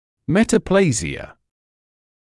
[ˌmetə’pleɪzɪə][ˌмэтэ’плэйзиэ]метаплазия